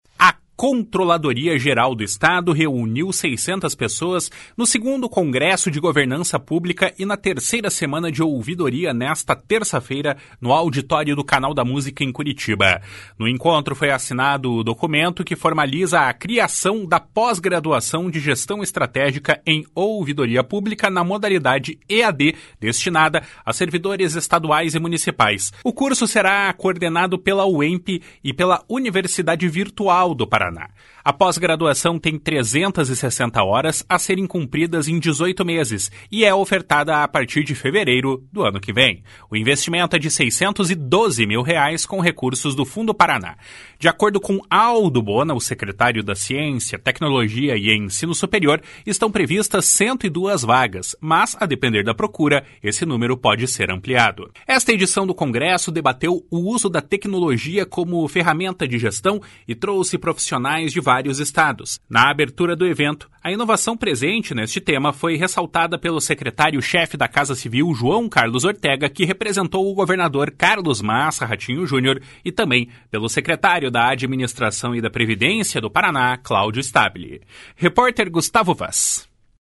De acordo com Aldo Bona, secretário de Ciência, Tecnologia e Ensino Superior, estão previstas 102 vagas, mas, a depender da procura, esse número poderá ser ampliado. // SONORA ALDO BONA //